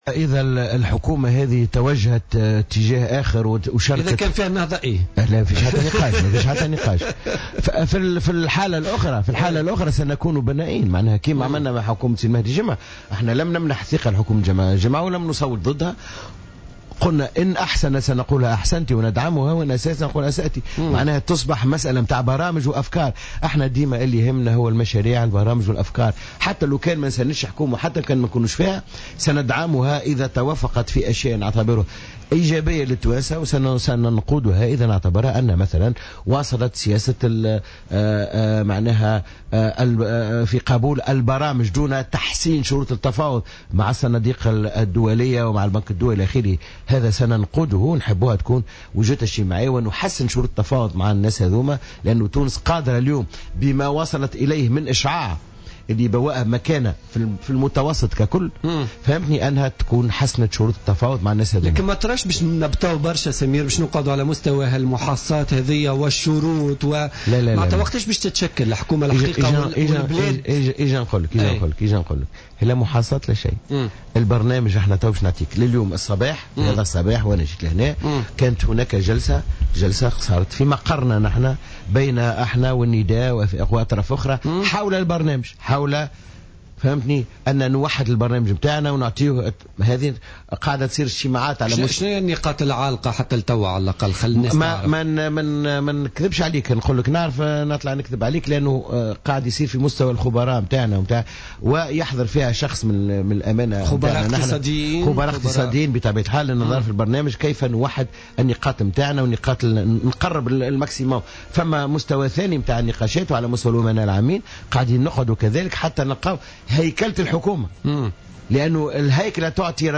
Le secrétaire général du parti Al Massar, Samir Taieb a indiqué au micro de Jawhara Fm qu’une séance a eu lieu ce lundi matin au siège d’Al Massar avec des représentants des partis Afek et Nidaa Tounes autour du programme pour la formation du nouveau gouvernement.